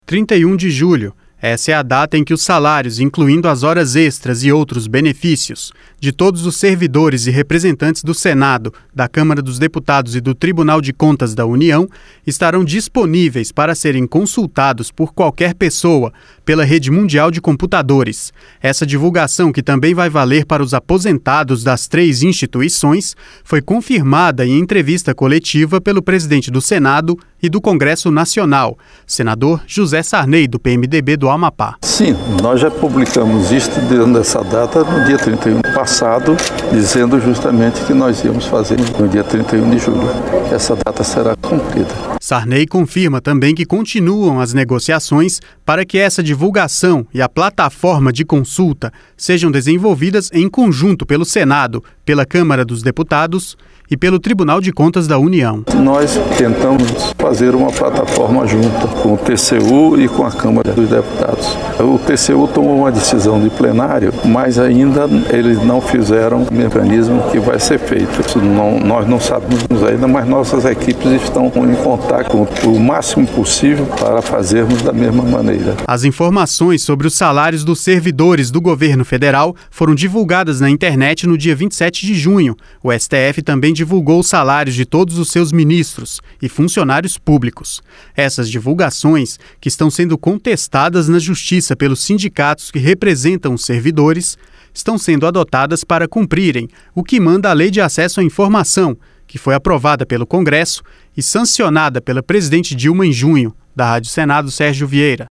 Esta divulgação, que também vai valer para os aposentados das 3 instituições, foi confirmada em entrevista coletiva pelo presidente do Senado e do Congresso Nacional, senador José Sarney do PMDB do Amapá.